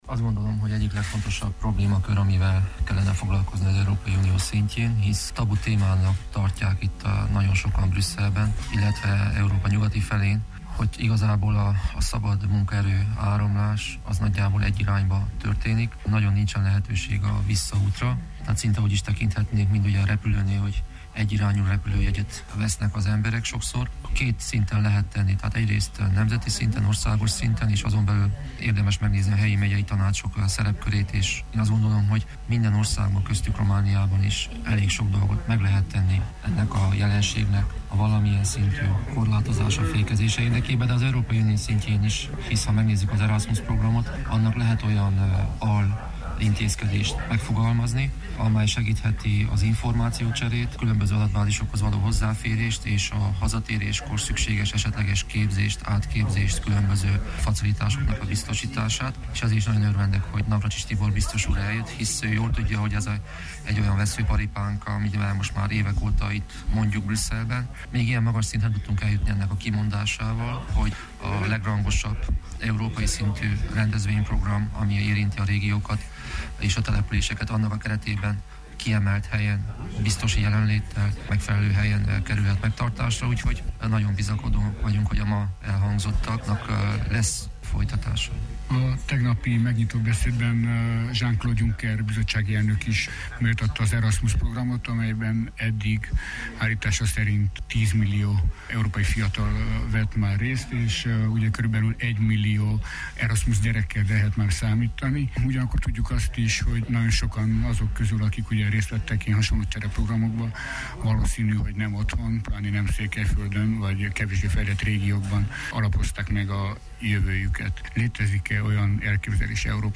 A fiatalok, és elsősorban a képzett fiatalok kivándorlása jelenti az egyik legnagyobb veszélyt Romániára, fejtette ki Hargita megye tanácselnöke, Borboly Csaba Brüsszelben, az Európai Városok és Régiók hete alkalmával szervezett konferencián.